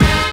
SWINGSTAB 9.wav